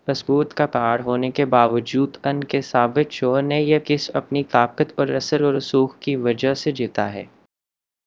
deepfake_detection_dataset_urdu / Spoofed_TTS /Speaker_03 /261.wav